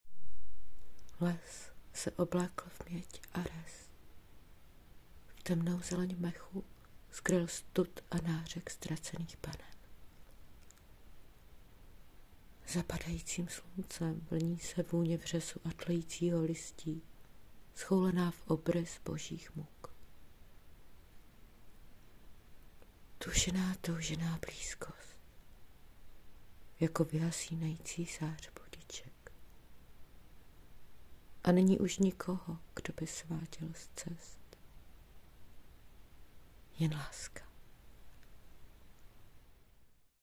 bravurní přednes!
(Ten přednes se mi zdá být poněkud emociálně přetažený, až plačtivý...ale chápu, jak to asi prožíváš, sám jsem nikdy nedokázal recitovat vlastní poezii s dostatečným nadhledem.)